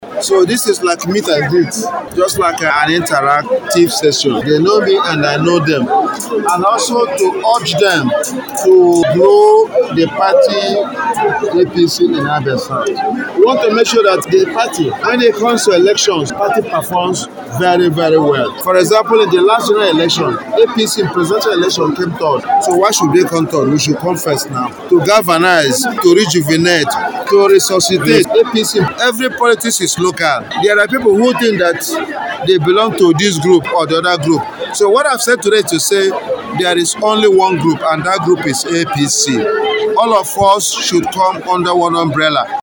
Hon. Nkwonta who made this known during an Agenda Setting Meeting of APC members in Abia South Senatorial Zone comprising of Aba North, Aba South, Ugwunagbo, Ukwa East, Ukwa West and Obingwa, which held at his country home Akwete, maintained that APC in Abia South must galvanize to win elections across board in 2027 stressing that this can only be achieved through membership drive and party dominance as he called on them to bring in more committed members into the party.